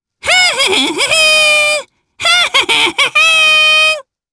Lakrak-Vox_Hum_jp.wav